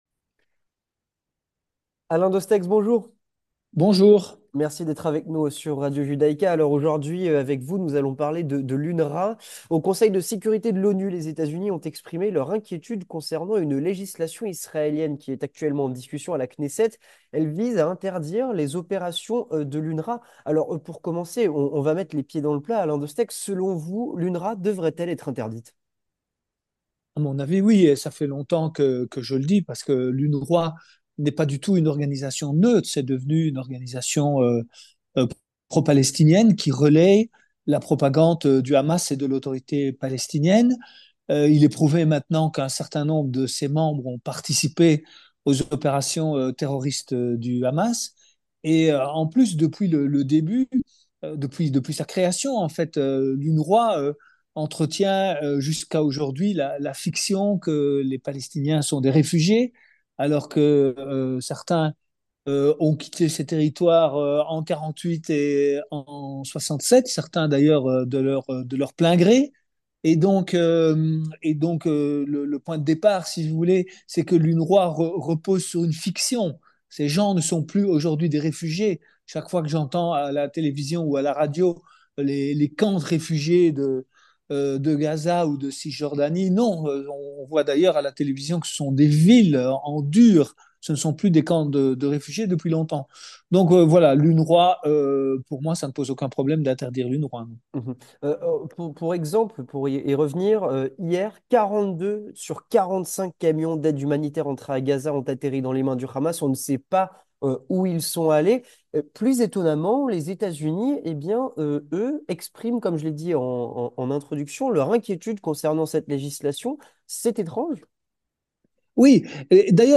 Avec Alain Destexhe, sénateur honoraire et ancien président de Medecins Sans Frontières